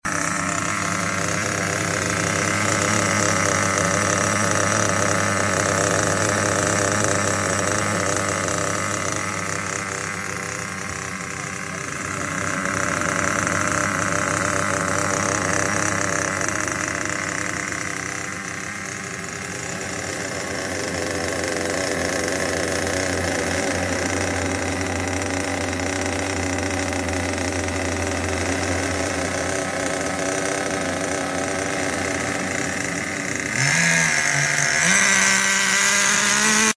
Prima accensione, Kyosho MP10 RTR! sound effects free download